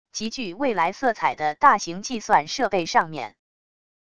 极具未来色彩的大型计算设备上面wav音频